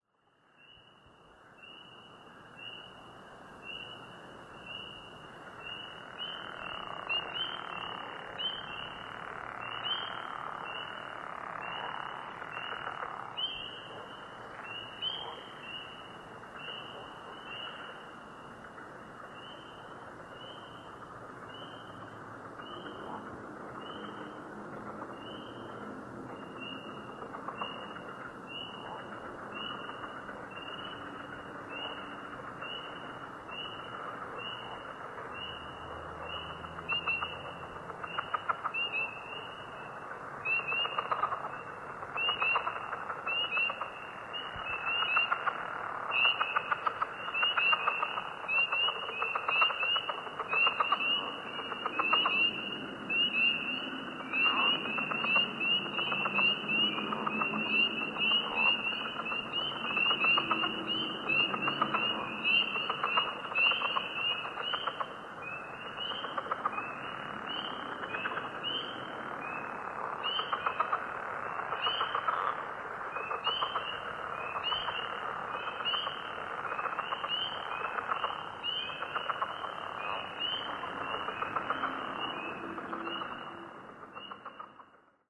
These are all night recordings, which should become obvious, and all recorded right from the backyard.
All clips had a little noise reduction done to cut down the normal background hiss, and one was amplified a little, but for all, I’d recommend headphones.
On another night, we had a slightly different set of sounds.
Up front, the drawn-out creaking growl is likely a gopher frog (Lithobates capito,) but now some of the muttering, duck-like calls can be heard individually, repeating a few times – these lend weight to being wood frogs.
All but a couple of these seemed to emanate from the far side of the pond, which is marshy and soft, so getting up close and spotting them might be tricky, more so by the headlamp, though we’ll see what happens.
0325ManyFrogs.mp3